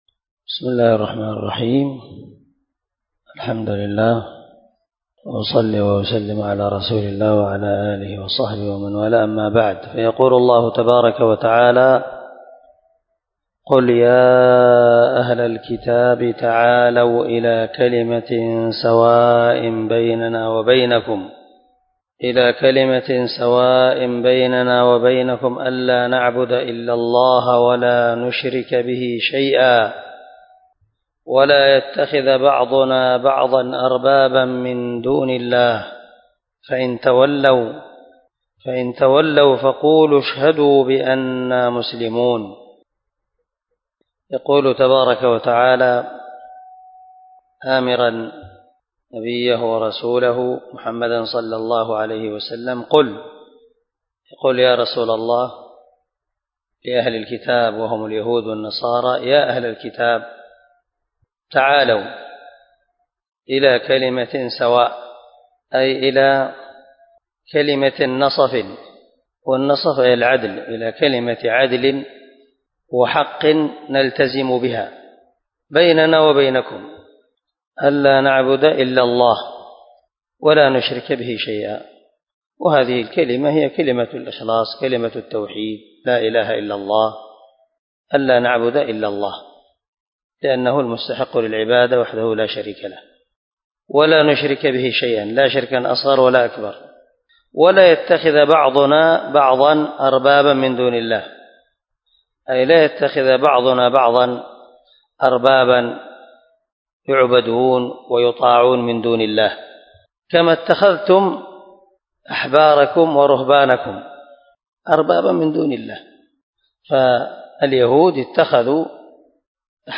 175الدرس 20 تفسير آية ( 64 – 68 ) من سورة آل عمران من تفسير القران الكريم مع قراءة لتفسير السعدي